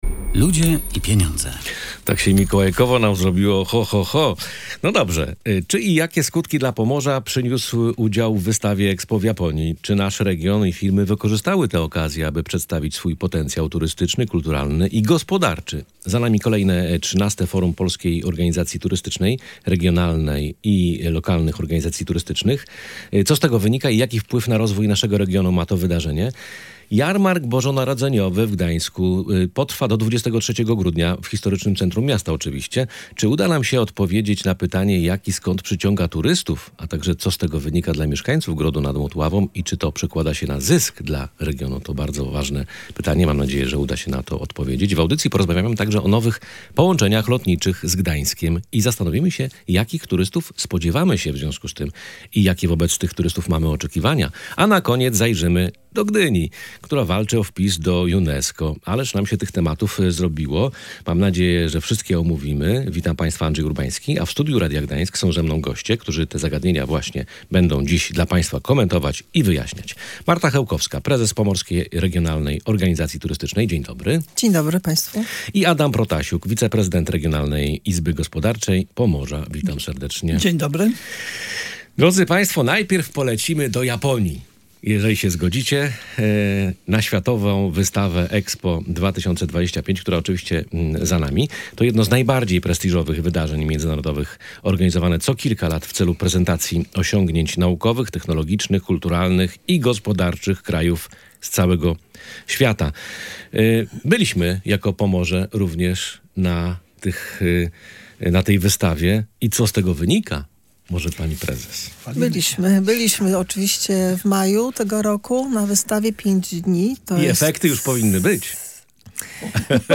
Między innymi o tym rozmawialiśmy w audycji „Ludzie i Pieniądze”.